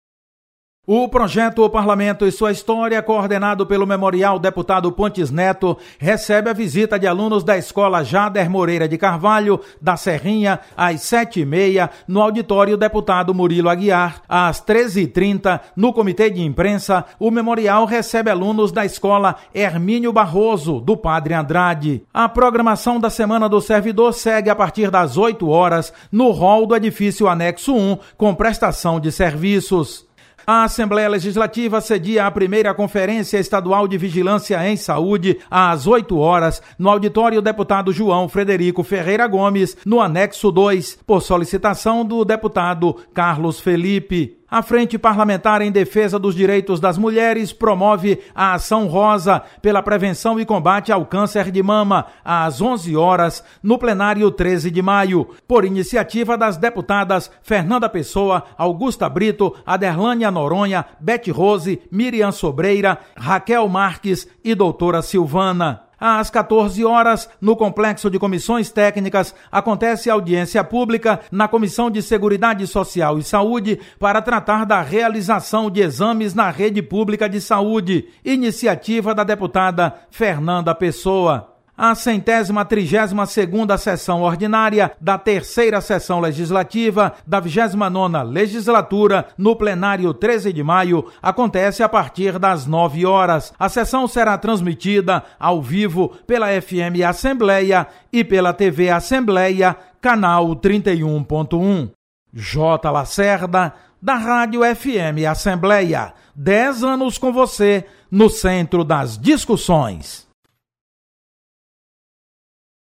Acompanhe as atividades de hoje da Assembleia Legislativa com o repórter